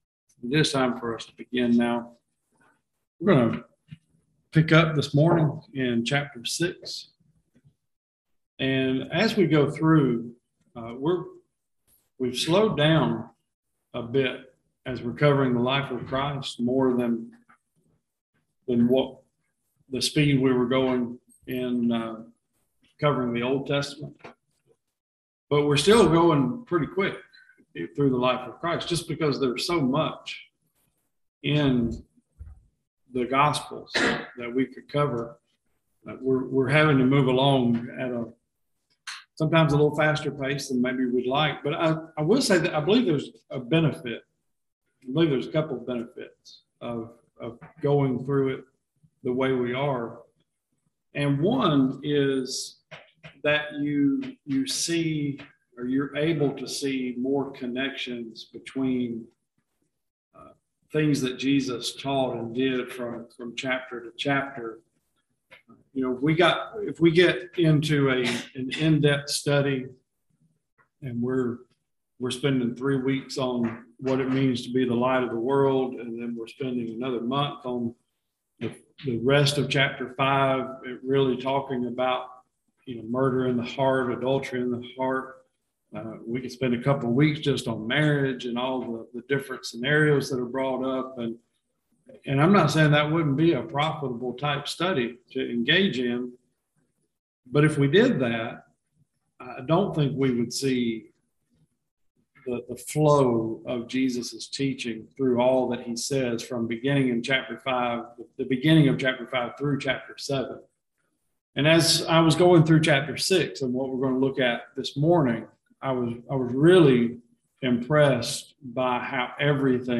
Matthew 6:1-34 Service Type: Bible Classes Who are we serving?